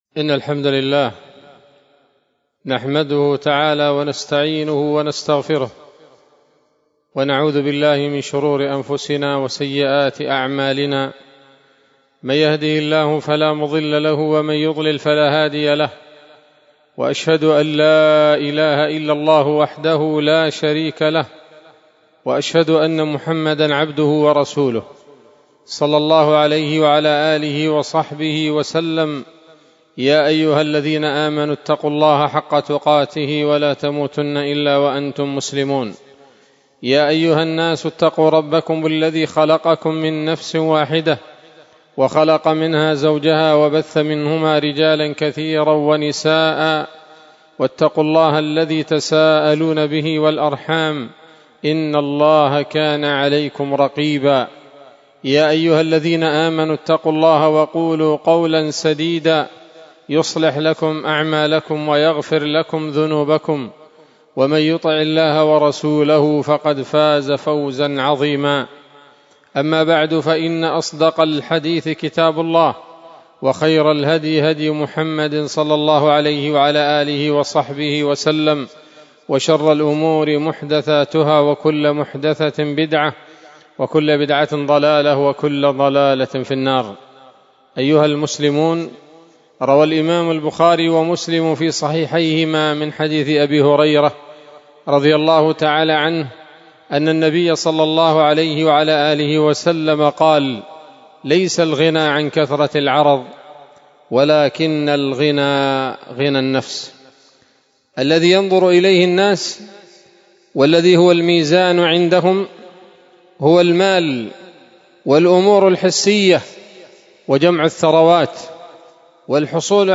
خطبة جمعة بعنوان: ((القناعة كنز لا يفنى )) 9 جمادى الأولى 1447 هـ، دار الحديث السلفية بصلاح الدين